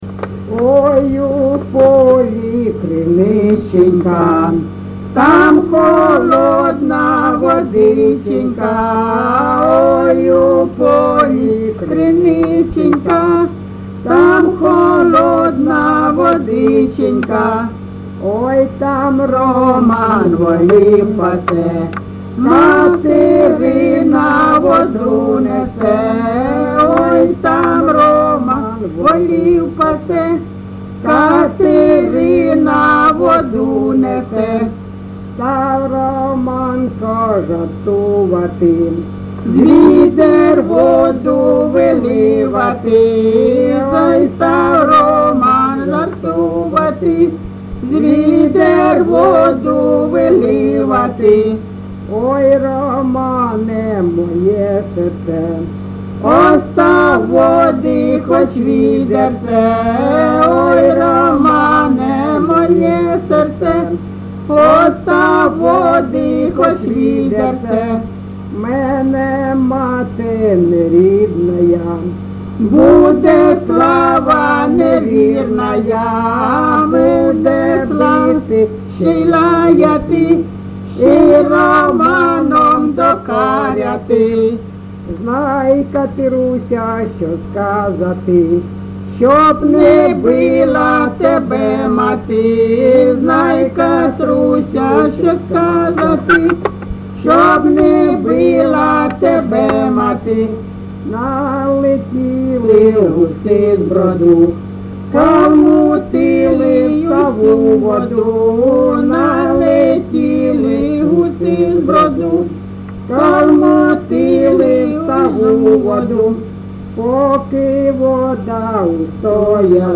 ЖанрПісні з особистого та родинного життя
Місце записус. Калинове Костянтинівський (Краматорський) район, Миколаївська обл., Україна, Слобожанщина